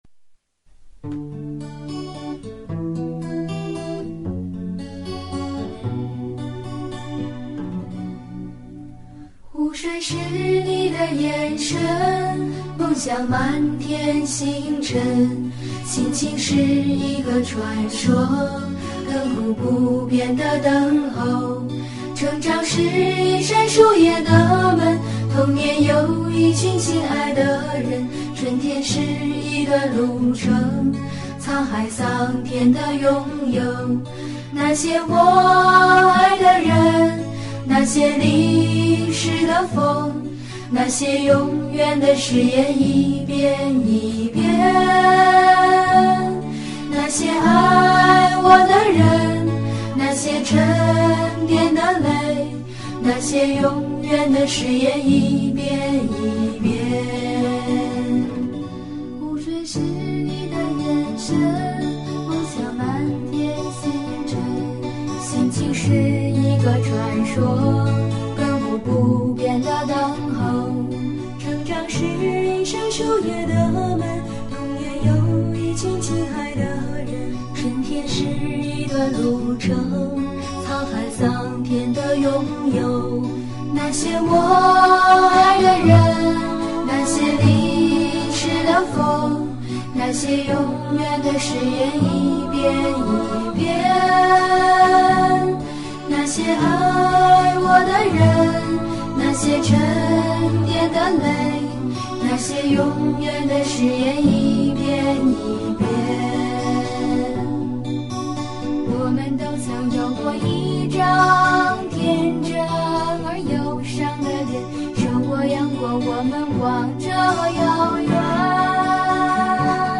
怀旧音乐
伤感音乐